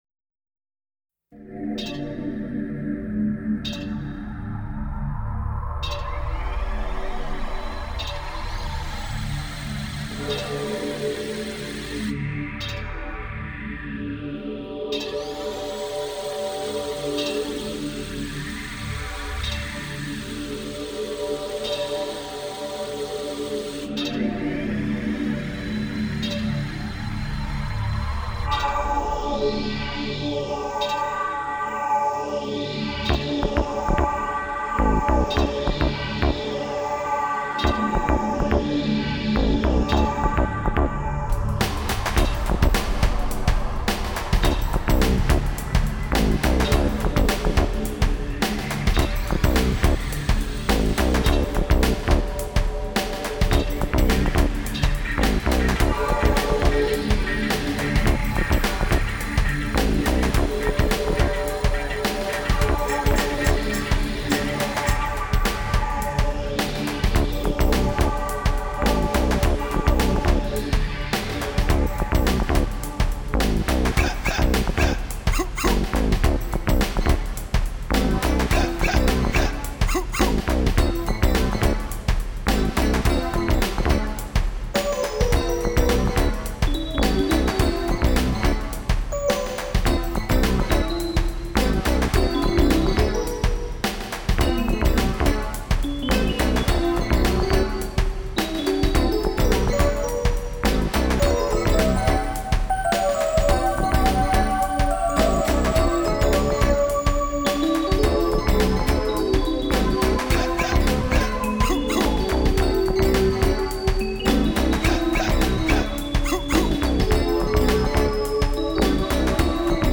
ALL INSTRUMENTAL SOLO PROJECTS